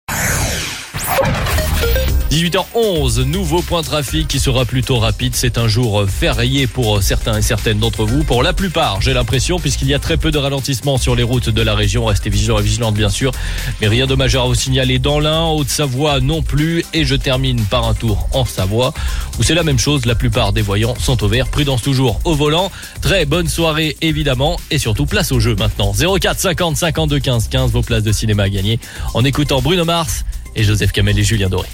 Info trafic